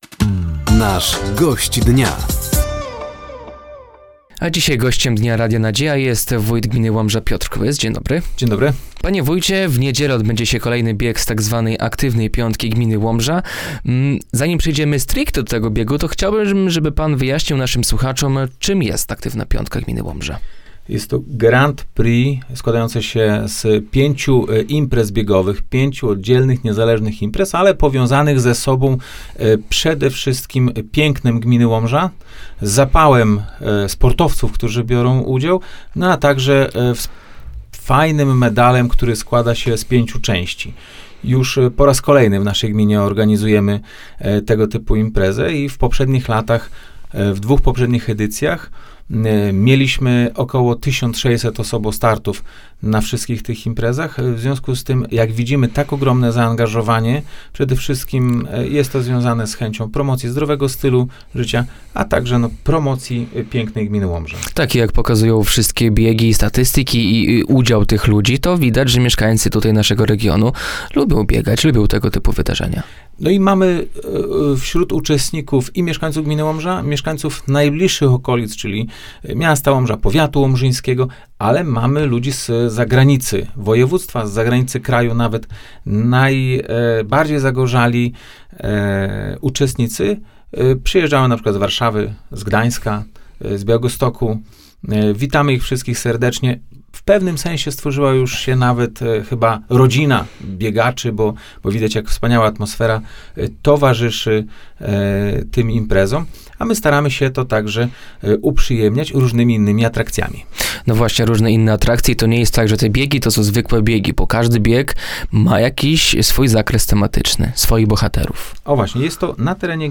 GD: Piotr Kłys, wójt gminy Łomża - Radio Nadzieja
Gościem Dnia Radia Nadzieja był wójt gminy Łomża, Piotr Kłys. Rozmowa dotyczyła między innymi zbliżającej się Kurpiowskiej Majówki u Stacha, rosnącej liczby mieszkańców gminy i pozyskanych pieniędzy z programu Czyste Powietrze.